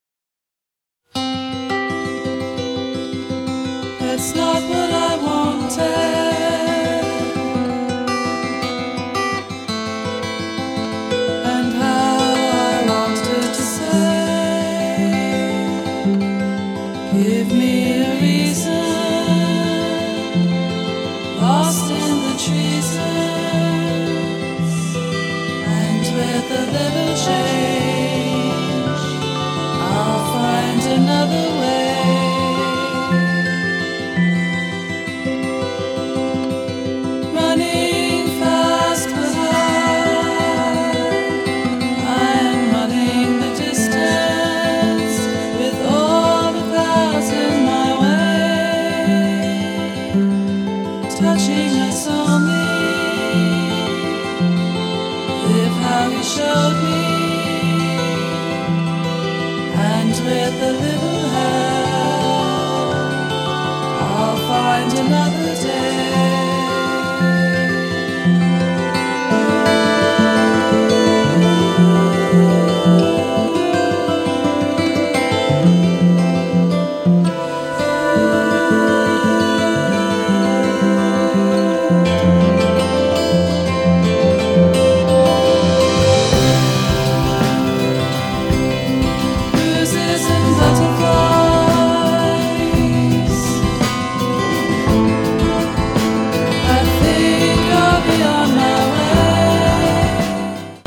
ブリティッシュ・フォークと西海岸のエッセンスが混じり合ったようなサウンドに魅了されてしまいますよ！